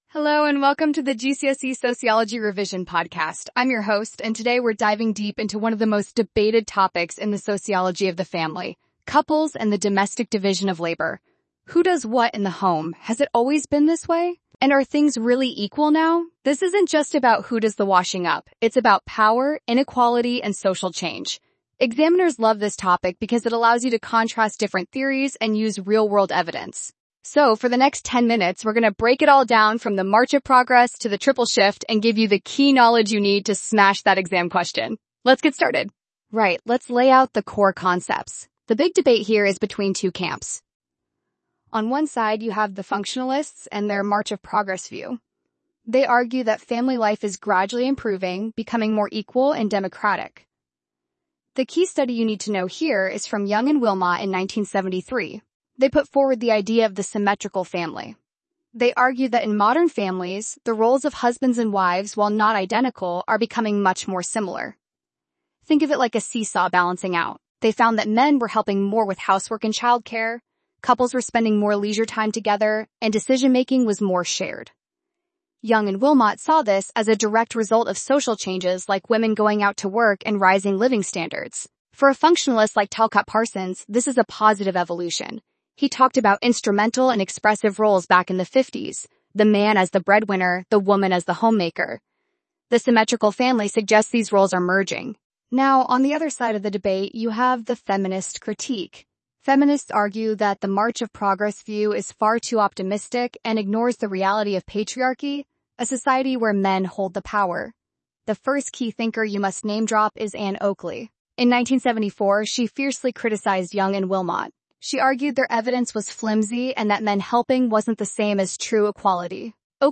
(Intro Music - Upbeat and modern, fades after 5 seconds)
(Transition sound effect - a gentle whoosh)